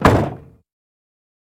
Cadillac 1964 Door Close